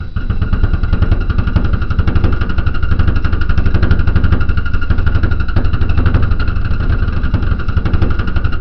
EXCAVATOR.wav